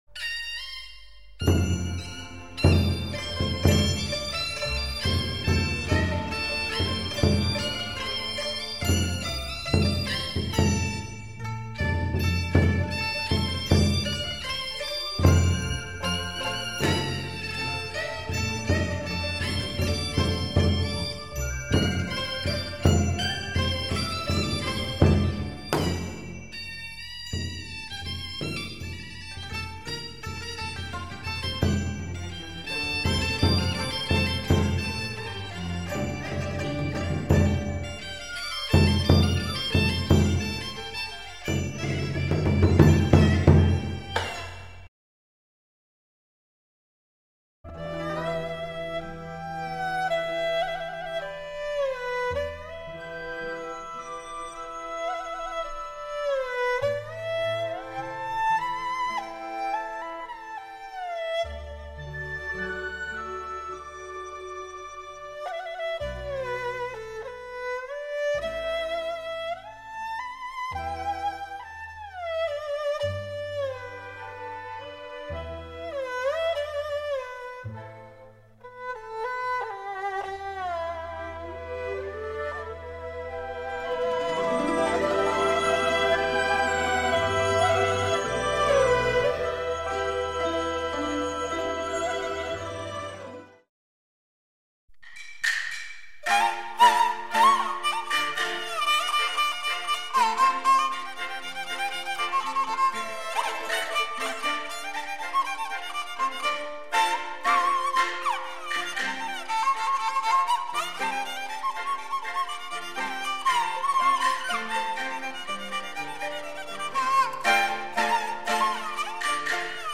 ——中国拉弦乐——